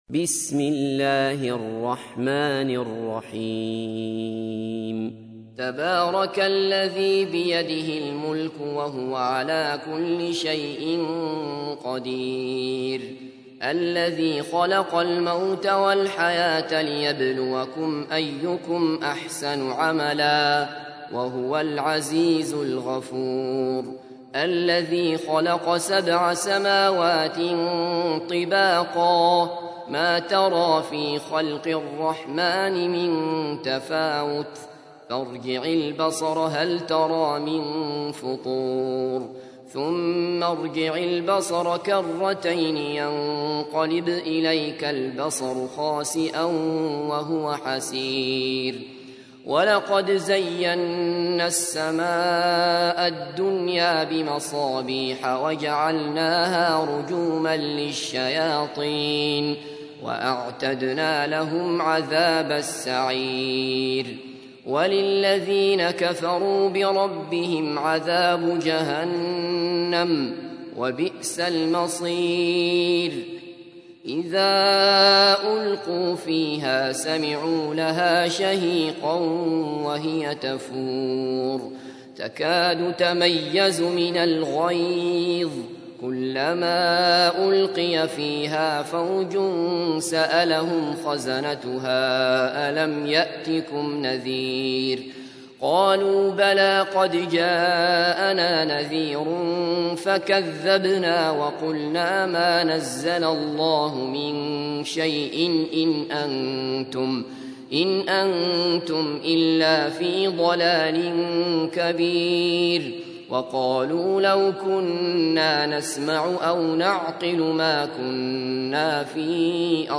تحميل : 67. سورة الملك / القارئ عبد الله بصفر / القرآن الكريم / موقع يا حسين